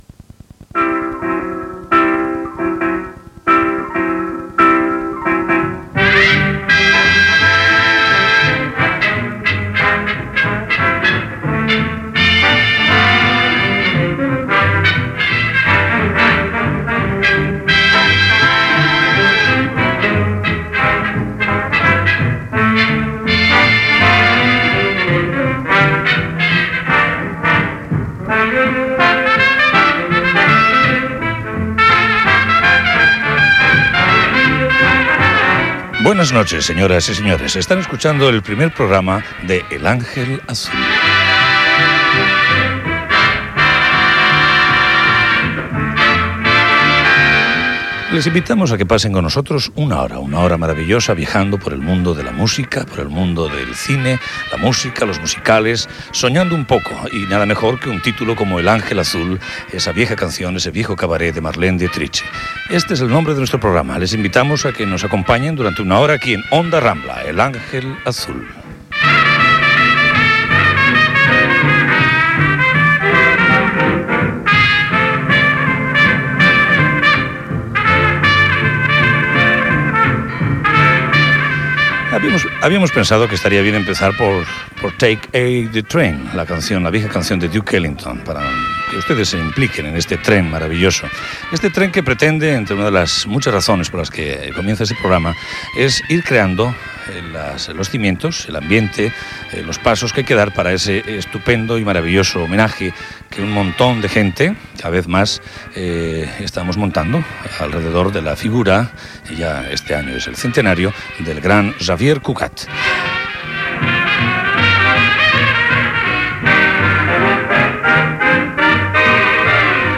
Programa realitzat des de Cibeles FM, Madrid, i emès també per Onda Rambla Catalunya.